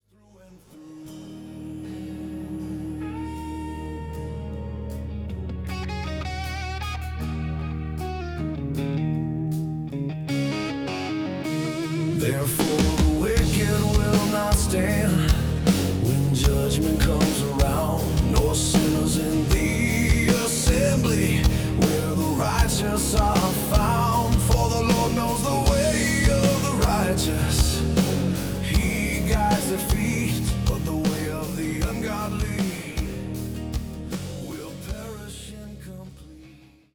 Wisdom delivered with conviction
Heavy electric guitar riffs and soaring solos
Driving bass lines that anchor each song
Distinctive rock drumming with dynamic fills
Raw, authentic Southern rock vocals